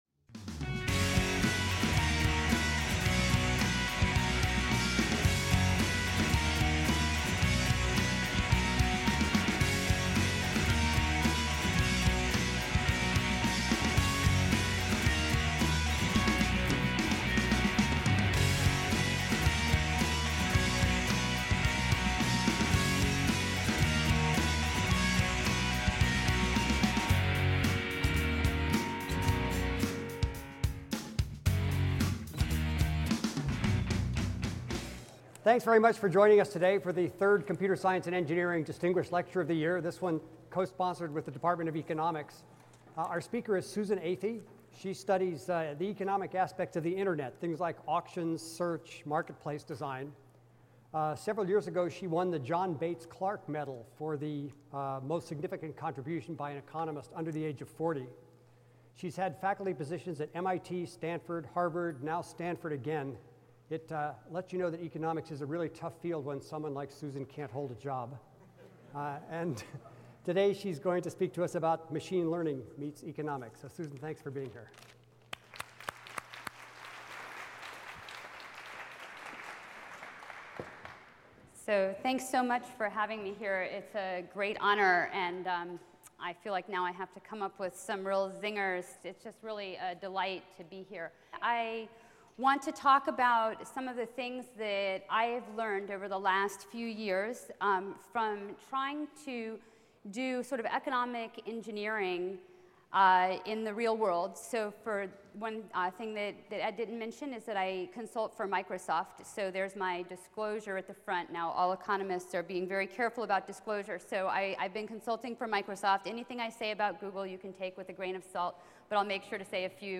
CSE Distinguished Lecture Series - co-sponsored with the UW Department of Economics
Atrium, Paul G. Allen Center for Computer Science & Engineering
I will also make some suggestions about research directions at the intersection of economics and machine learning. Bio: Renowned economist Susan Athey is a professor of economics at the Stanford Graduate School of Business.